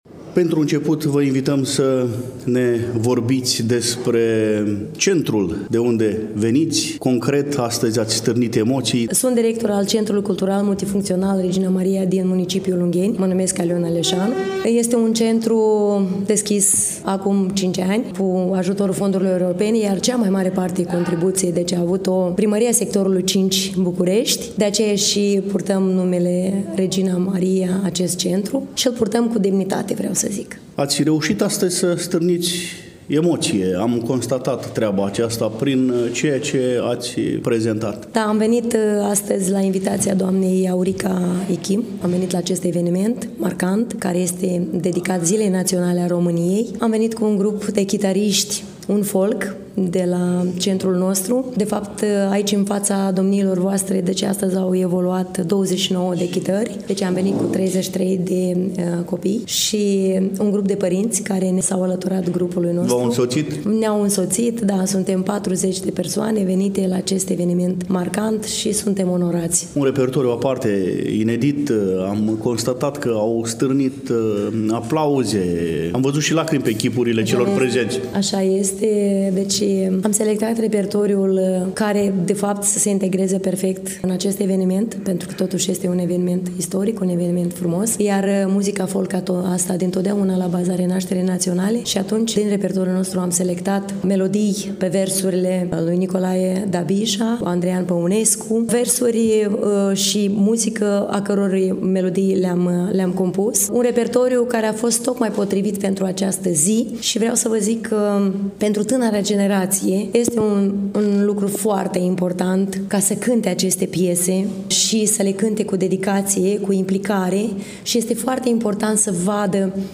Manifestarea a avut loc în ziua de vineri, 29 noiembrie 2024, începând cu ora 11 în incinta Palatului Braunstein din târgul Iașului.
Stimați prieteni, aproape de finalul evenimentului ne-am întâlnit și cu reprezentanții Grupulului de chitariști „UnFolk” al Centrului Cultural Multifuncțional „Regina Maria” din Ungheni, Republica Moldova,  grup care a prezentat un frumos și emoționant program artistic.